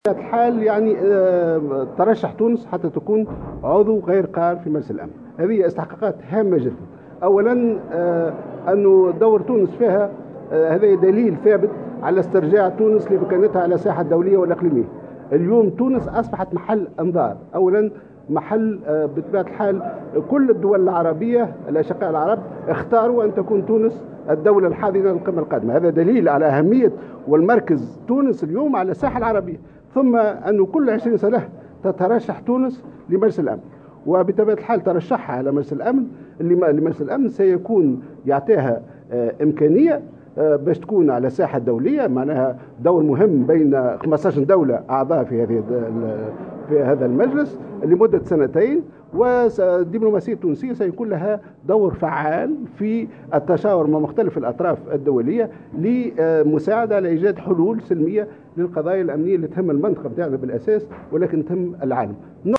وقال في تصريحات لـ "الجوهرة أف أم"، على هامش الندوة السنوية للبعثات الدبلوماسية، إن تونس تترشح لهذا المقعد كل 20 سنة تقريبا ما يوفر لها فرصة التواجد بصفة فاعلة بالساحة الدولية.